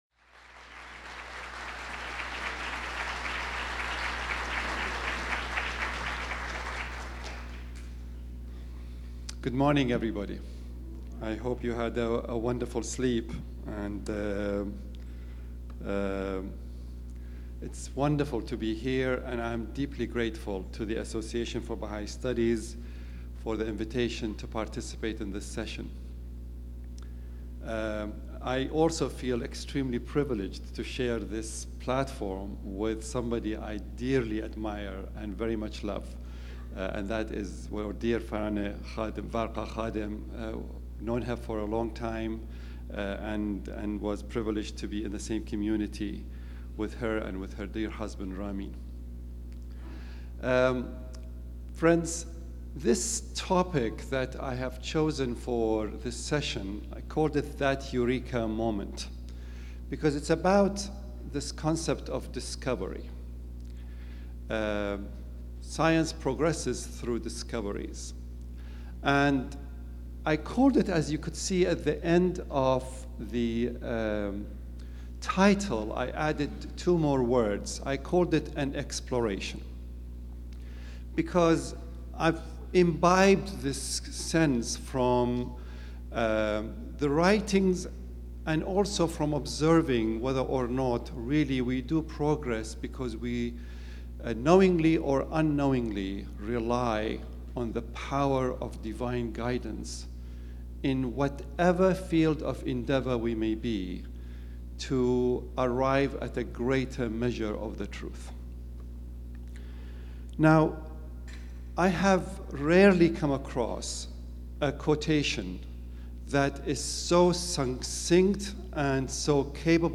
Abstract: This presentation addresses the role of inspiration in the convergence of intellect and spirit leading to scientific discovery. It examines inspiration as a means for a gradual and ever-expanding revelation of truth from the Source of sciences and arts for those committed to scientific endeavor and discovery.